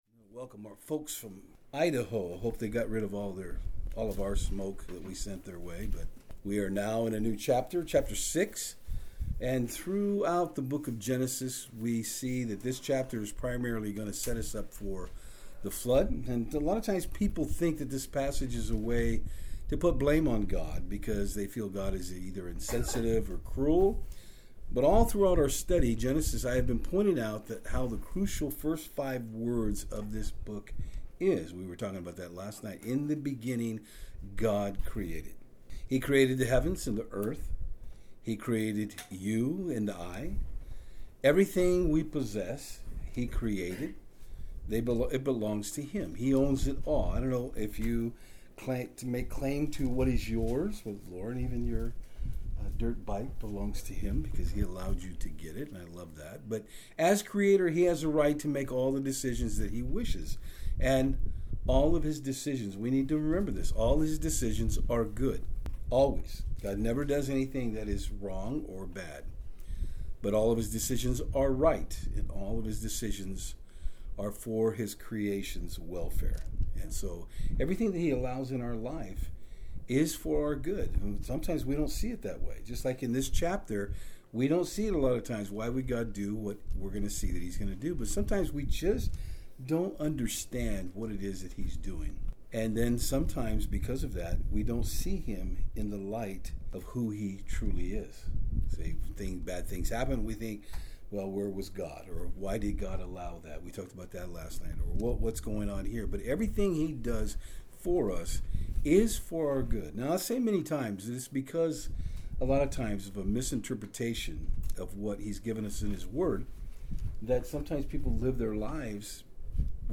Genesis 6:1-8 Service Type: Saturdays on Fort Hill It is hard to believe that God would grieve over something He created.